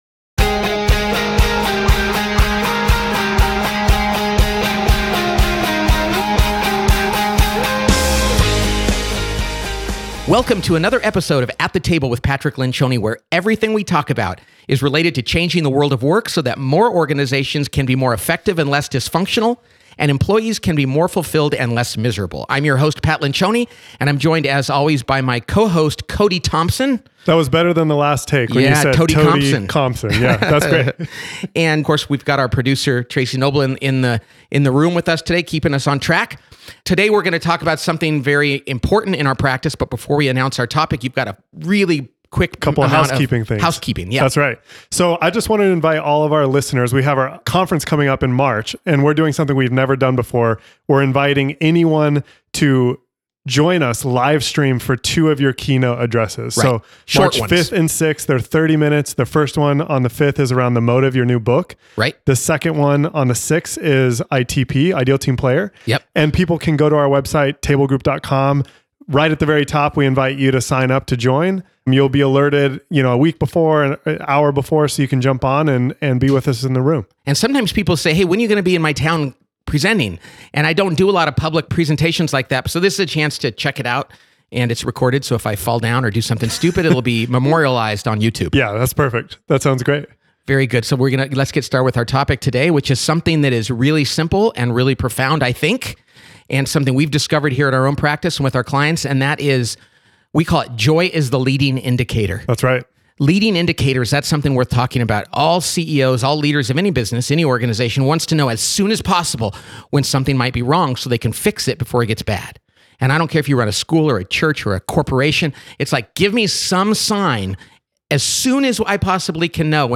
Play Rate Listened List Bookmark Get this podcast via API From The Podcast 1 2 Real conversations and practical advice for everyday leaders.